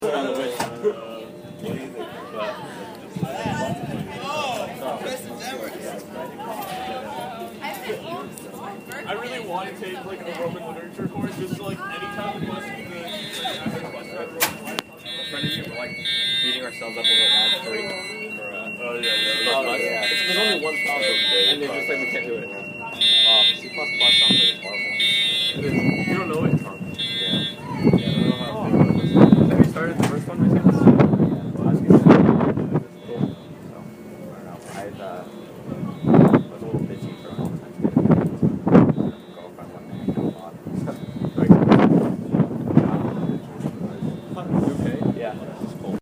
fire alarm – Hofstra Drama 20 – Sound for the Theatre
Location: Outside Alliance Hall — February 18, 2013, 2:05 pm
At times, the blaring of the alarm can be heard only faintly, but the doors to the building sporadically open to admit firefighters and public safety officers, at which point the alarm becomes much louder. The final sound is that of the wind blowing against the building, as the plateau outside Alliance Hall functions as a fairly strong wind tunnel.
Fire-Alarm-MP3.mp3